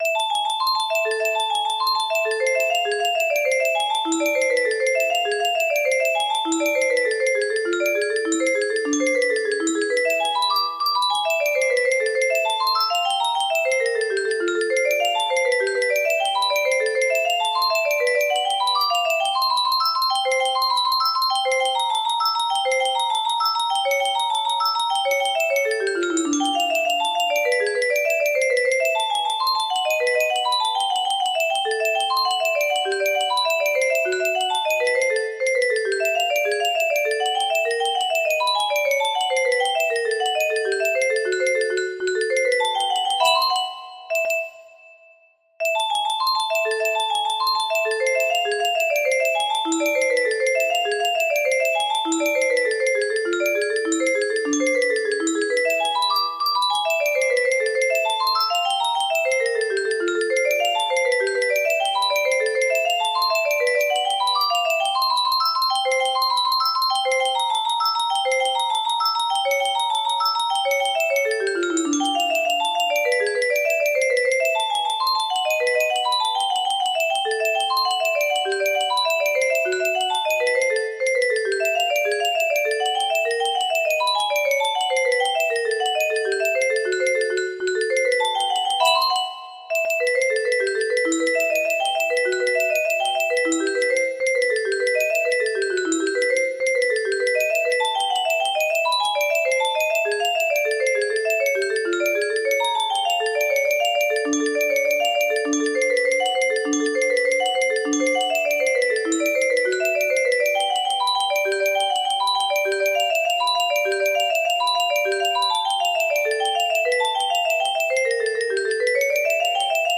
Bach partita la mineur Allemande music box melody